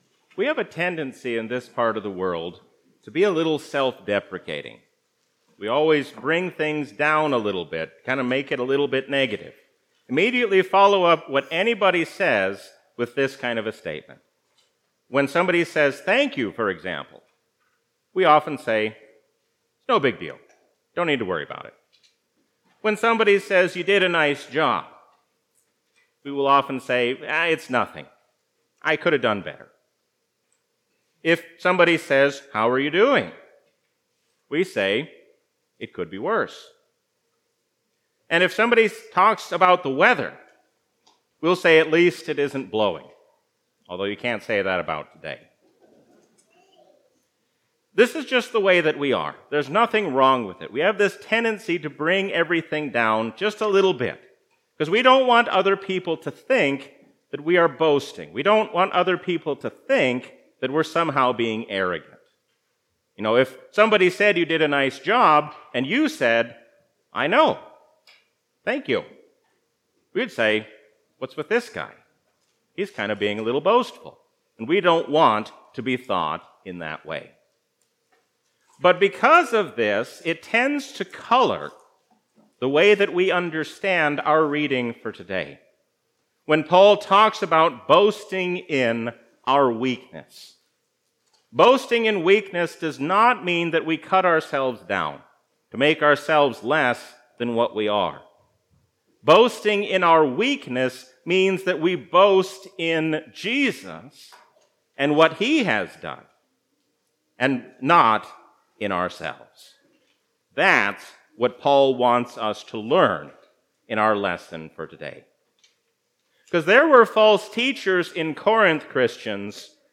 A sermon from the season "Gesimatide 2022."